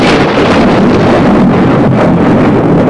Nasty Thunder Sound Effect
Download a high-quality nasty thunder sound effect.
nasty-thunder.mp3